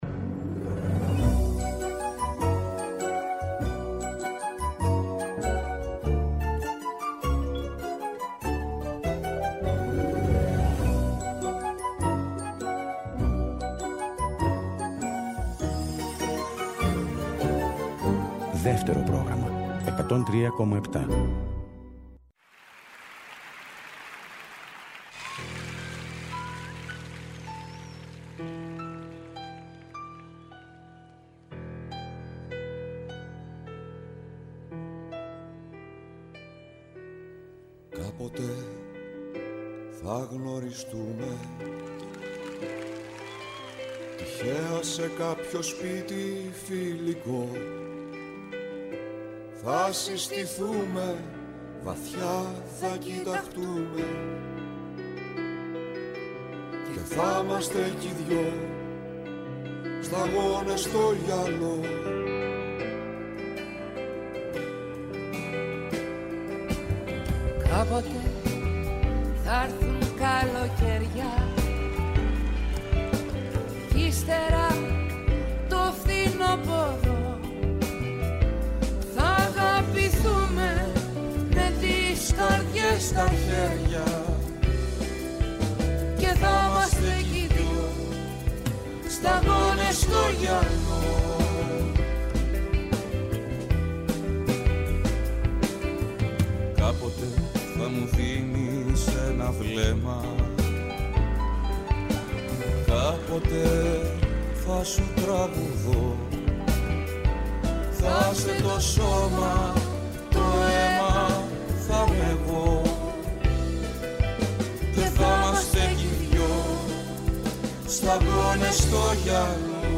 κάθε Σάββατο και Κυριακή στις 19.00 έρχεται στο Δεύτερο Πρόγραμμα με ένα ραδιοφωνικό – μουσικό road trip. ΔΕΥΤΕΡΟ ΠΡΟΓΡΑΜΜΑ